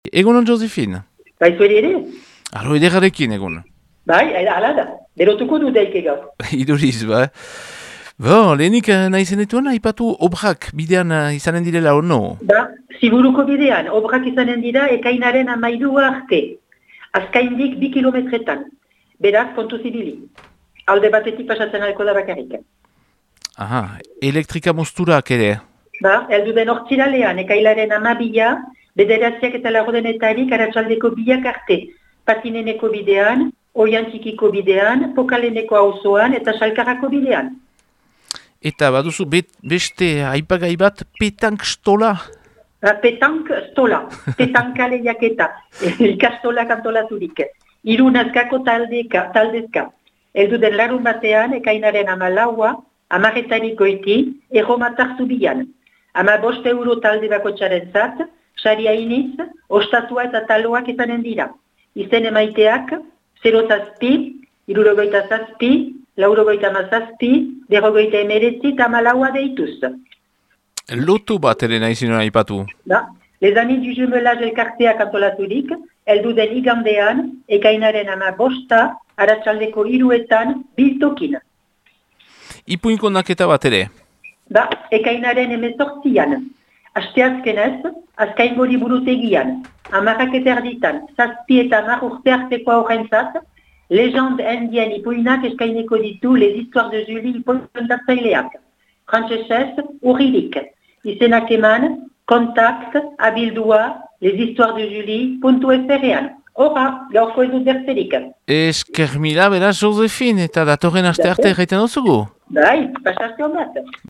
BERRIKETARIAK | AZKAINE